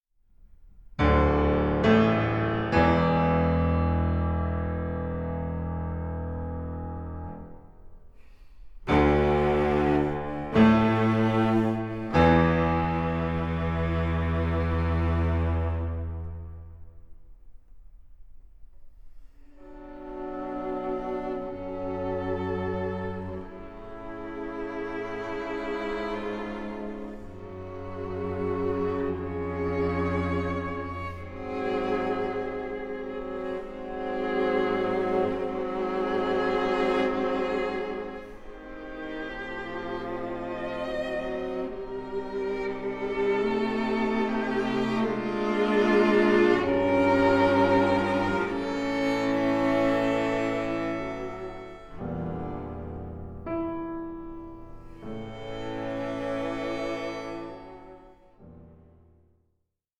for violin, piano and string quartet